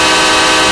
5 chime horn 5b.ogg